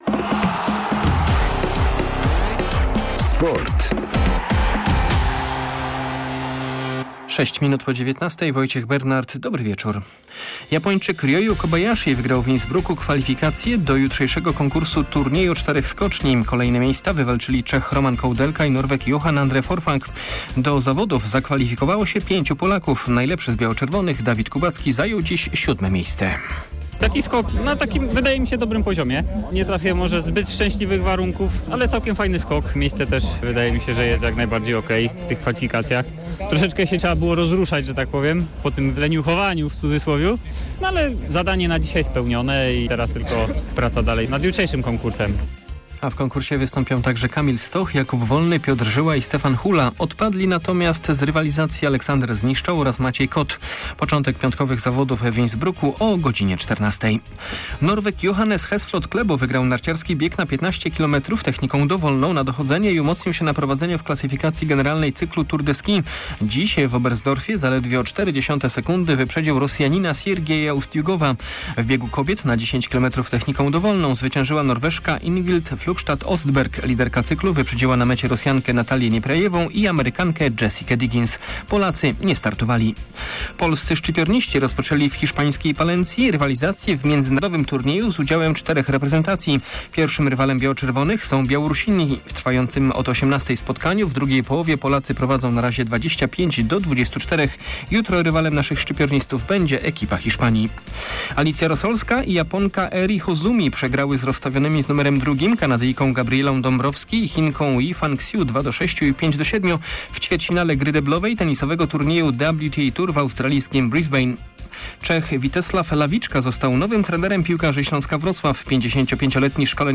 03.01 SERWIS SPORTOWY GODZ. 19:05